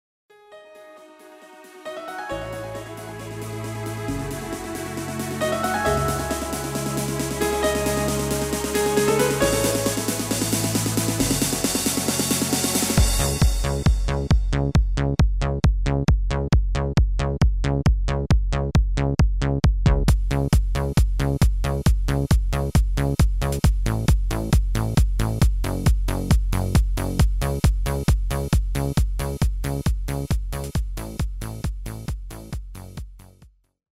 Demo/Koop midifile
Genre: Dance / Techno / HipHop / Jump
- Géén vocal harmony tracks
Demo = Demo midifile